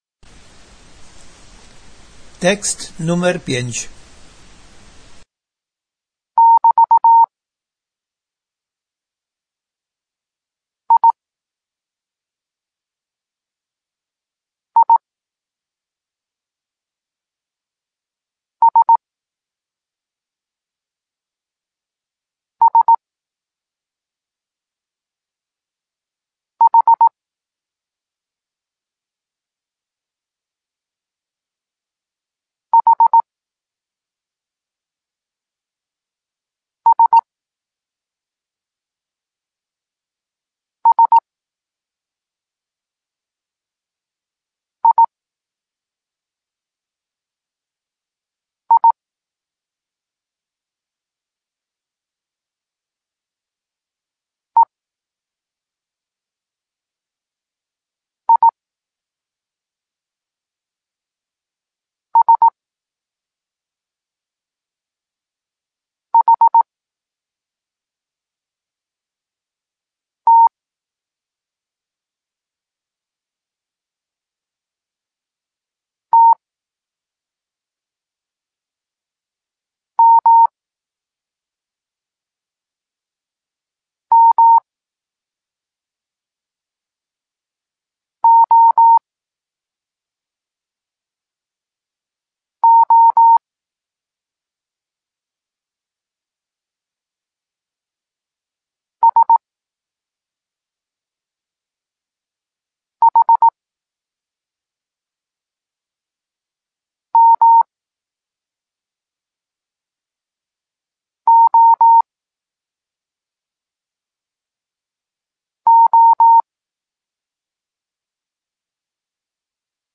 Tekst 5 – doskonalenie odbioru poznanych liter